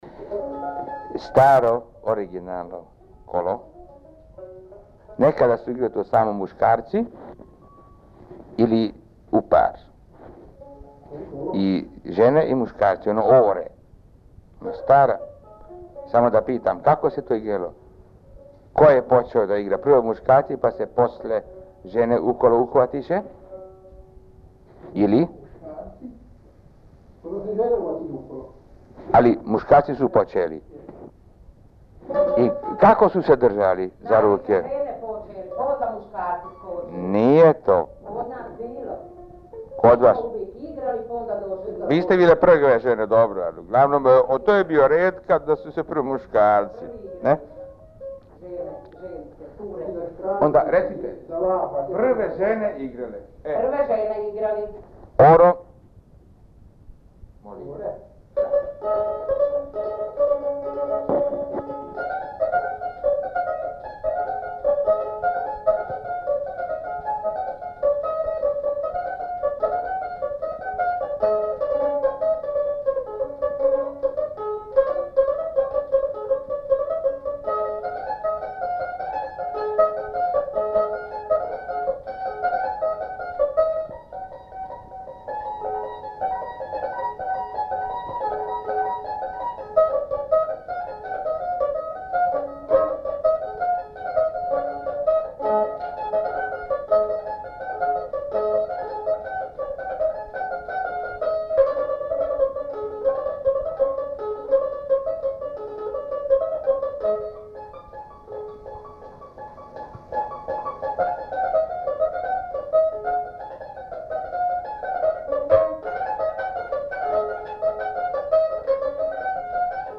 Tema: Instrumentalna i instrumentalno-pevana muzika
Mesto: Tukulja
tambura
Tradicionalna igra